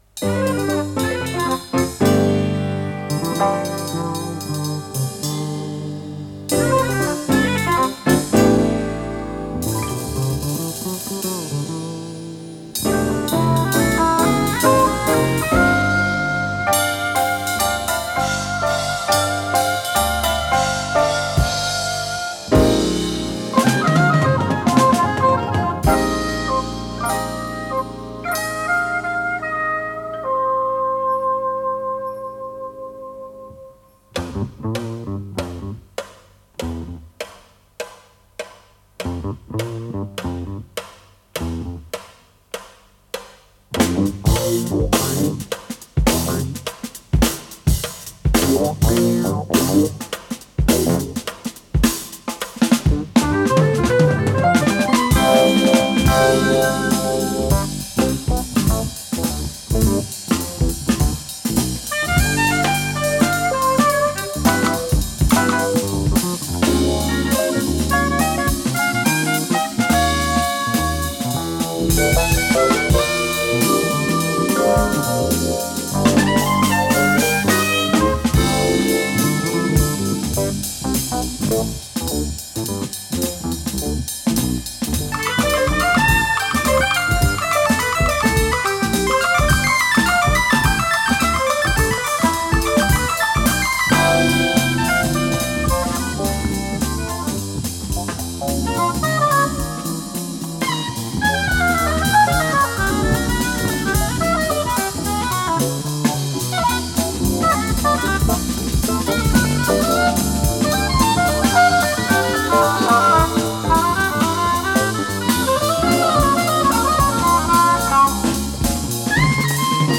клавишные инструменты
саксофон-сопрано
электро-бас
ударные
ВариантДубль моно